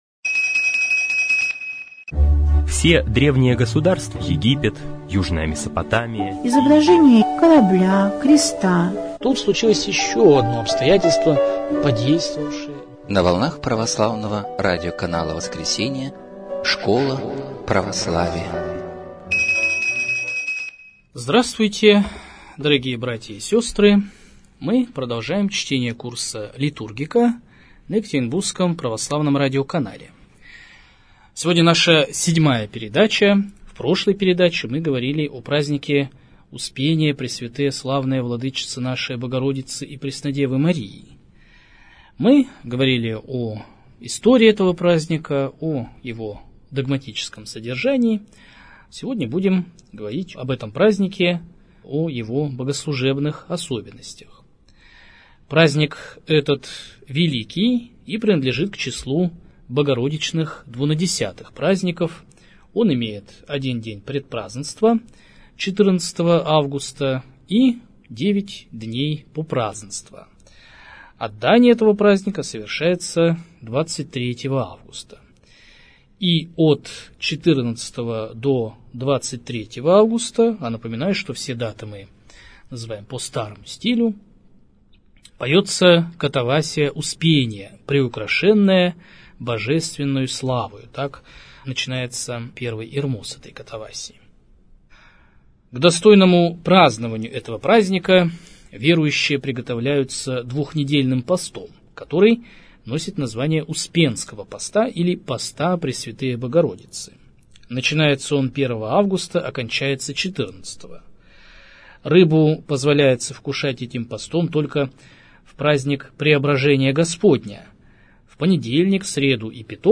liturgika_urok_7.mp3